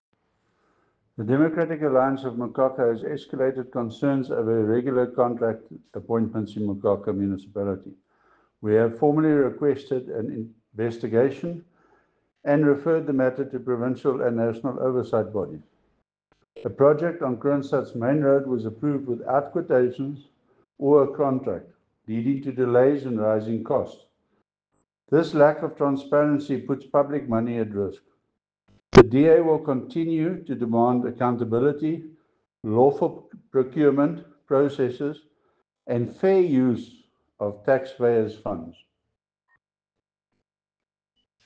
Afrikaans soundbites by Cllr Chris Dalton and